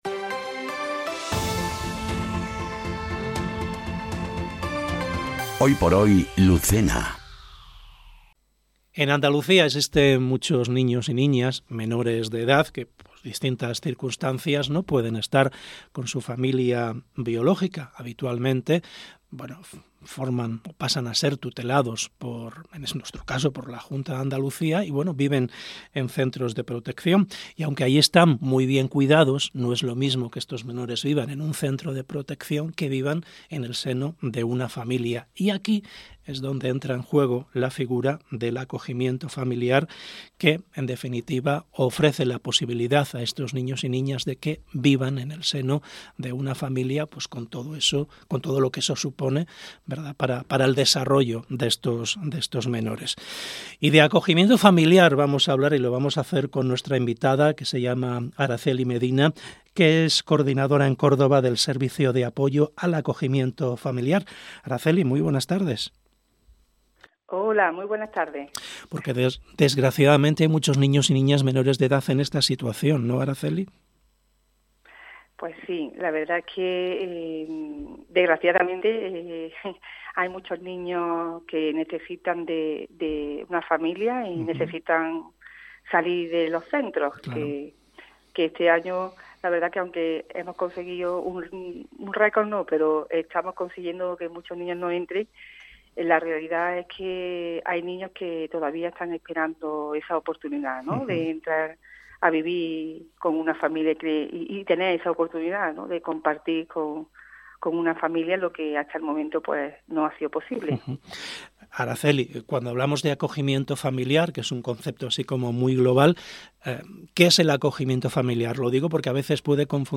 ENTREVISTA | Córdoba acogimiento familiar - Andalucía Centro